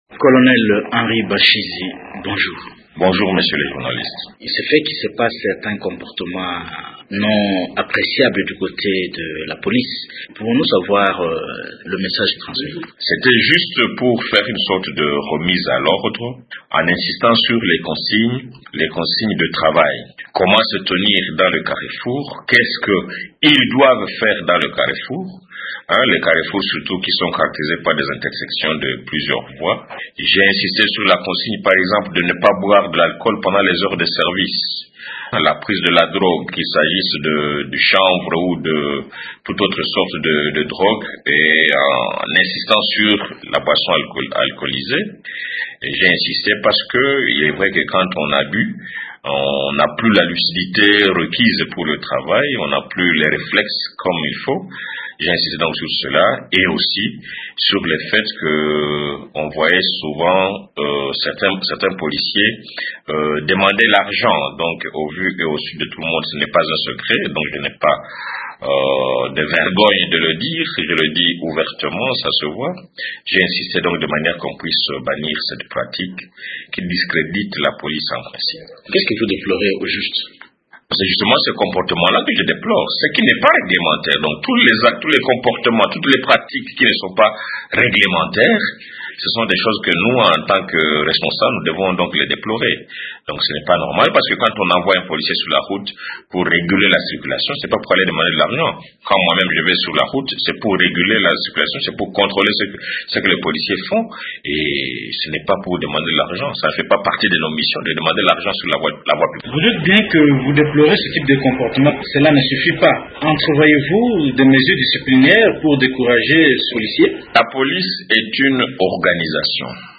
Le commandant de la police de circulation routière de Lubumbashi, Henri Bashizi est interrogé